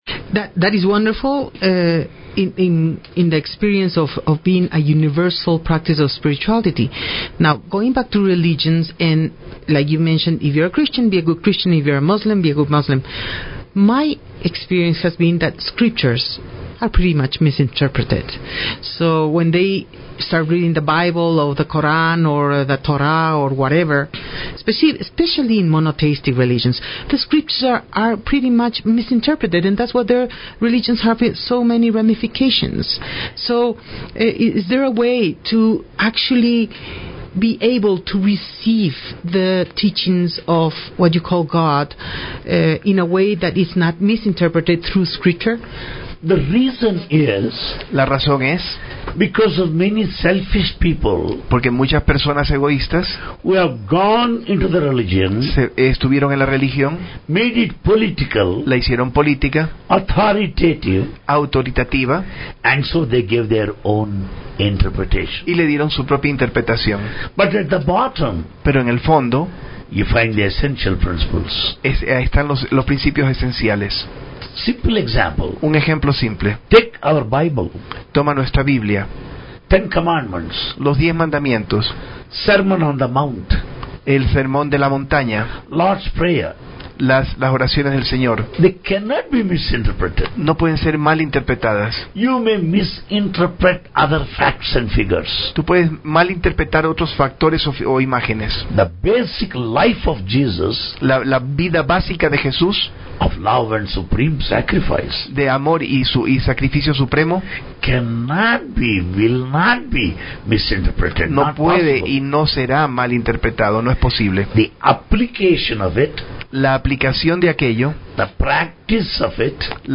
No es una emisora común, es una Radio Virtual, se llama Serenidad.
Pueden escuchar la 2da parte de 3 de la entrevista haciendo click en este enlace Radio Serenidad – Programa Reflexiones – 30 mayo 2012 – 2
Sólo las respuestas fueron traducidas simultáneamente al español.